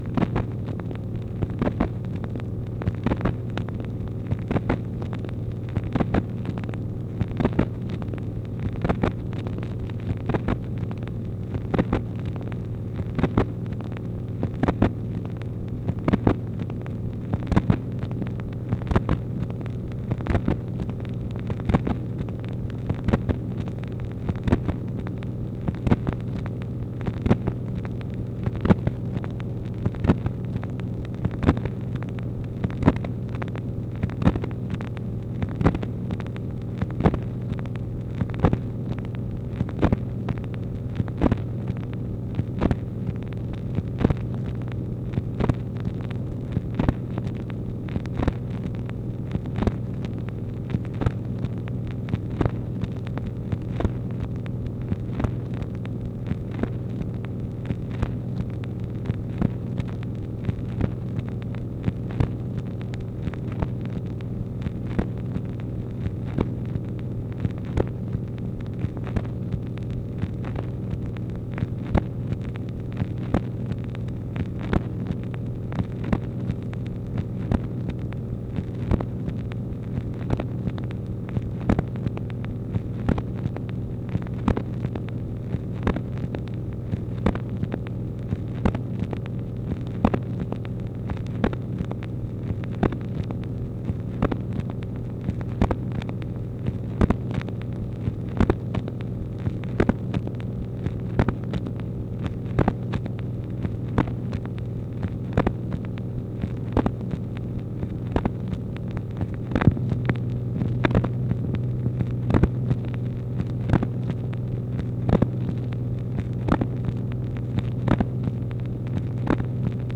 MACHINE NOISE, June 12, 1964
Secret White House Tapes | Lyndon B. Johnson Presidency